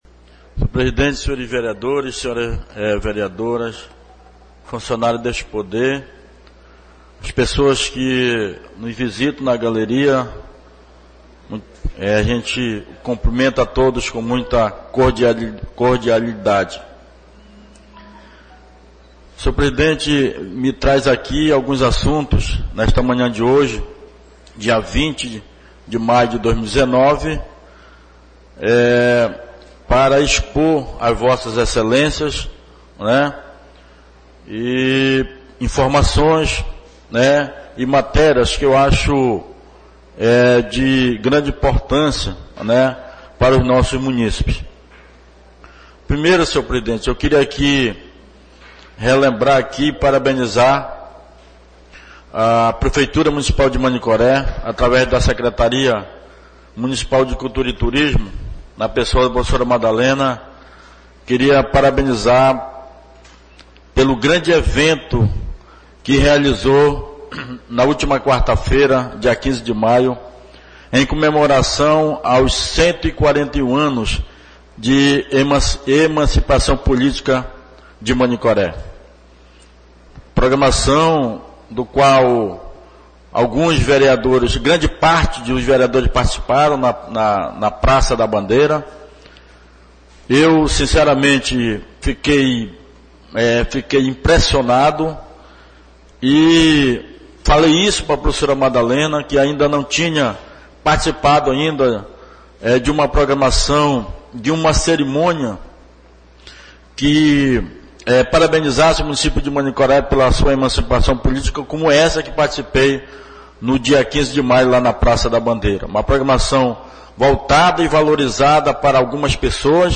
Oradores do Expediente (28ª Sessão Ordinária da 3ª Sessão Legislativa da 31ª Legislatura)
2_fala_nelson.mp3